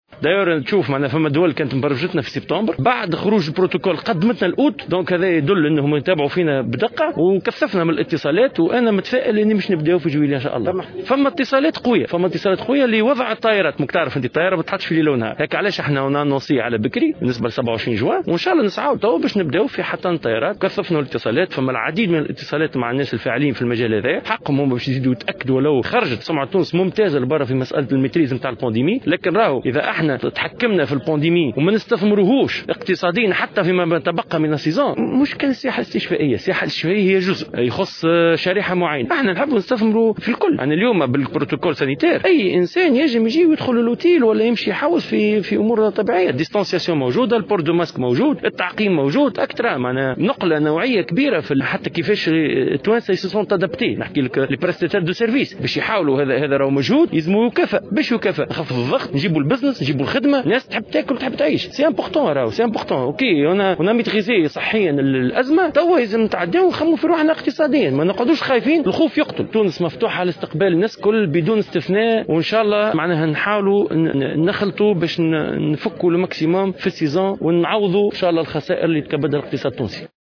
وأكد التومي في تصريح لممثلي وسائل الإعلام، خلال زيارة ميدانية إلى مطار تونس قرطاج، أنه لا يمكن حاليا تقديم تقديرات لعدد السياح الذين سيحلّون بتونس في الموسم الصيفي الحالي والذي سيقتصر هذا العام على شهري جويلية وأوت، مشيرا إلى أن شركاء عالميين في مجال السياحة، عبّروا عن ارتياحهم لتمكّن تونس من السيطرة على وباء كورونا، مبدين إعجابهم بما حققته البلاد في هذا المجال، باعتبارها من أقل الدول تضررا، بفضل برنامجها الوقائي والعلاجي.